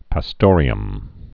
(pă-stôrē-əm)